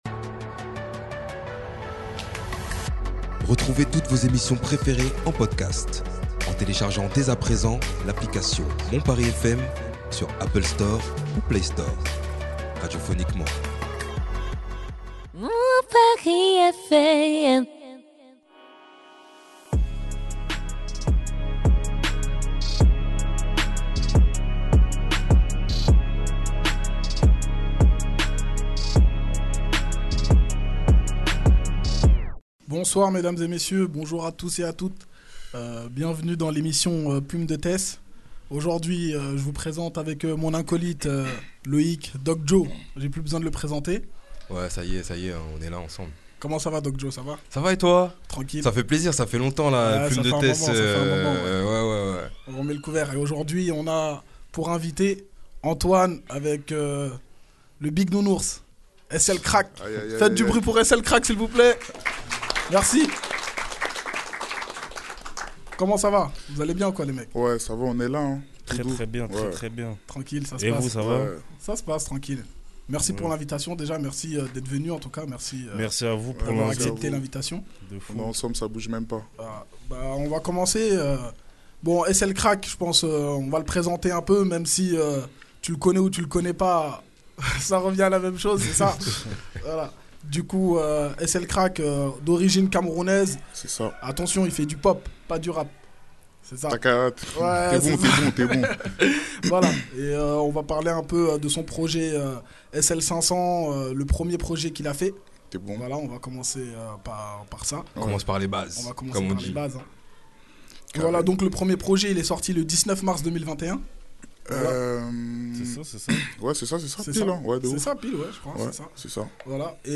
Et quoi de mieux pour finir en beauté que de le voir à l'œuvre en freestyle!